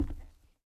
tbd-station-14/Resources/Audio/Effects/Footsteps/floor4.ogg